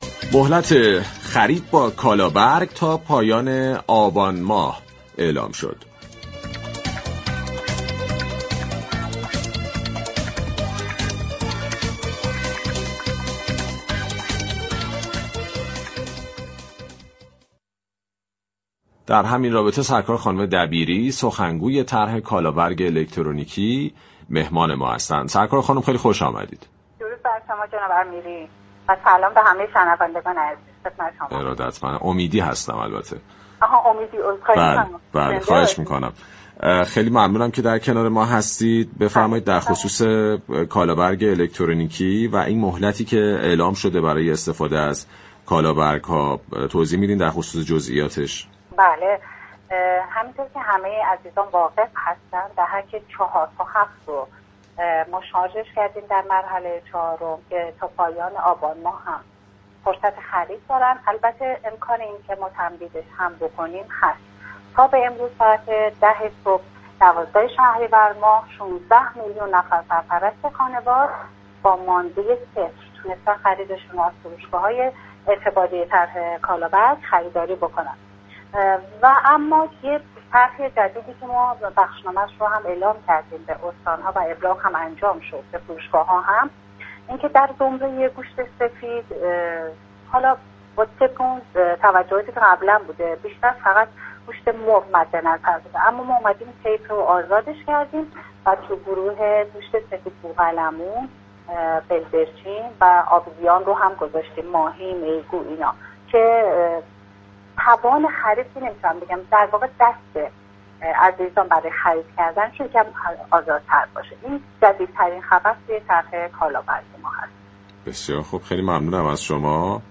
در گفت‌وگو با رادیو تهران